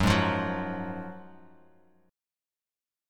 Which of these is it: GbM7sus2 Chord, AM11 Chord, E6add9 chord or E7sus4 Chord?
GbM7sus2 Chord